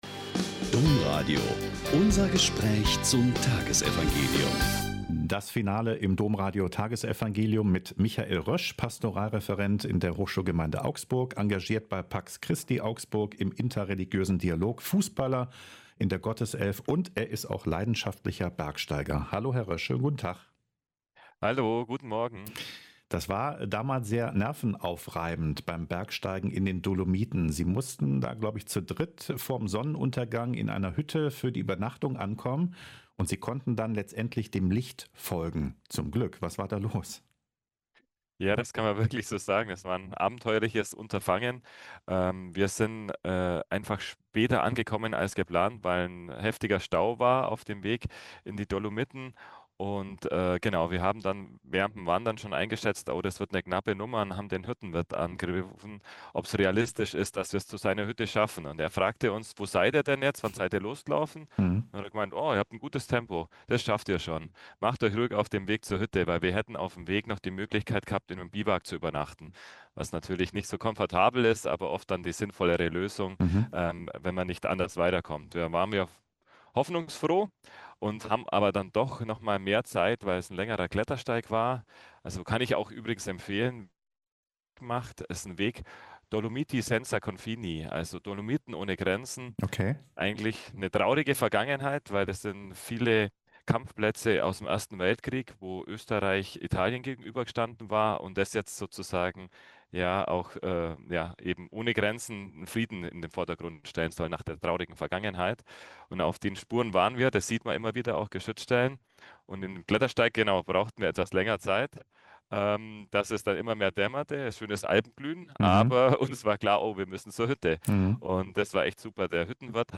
Lk 10,1-9 - Gespräch